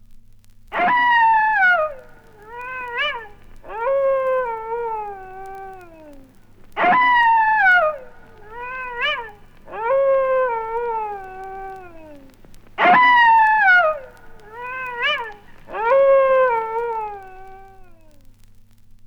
• wolf howling.wav
Recorded from Sound Effects - Death and Horror rare BBC records and tapes vinyl, vol. 13, 1977.
wolf_howling_sKg.wav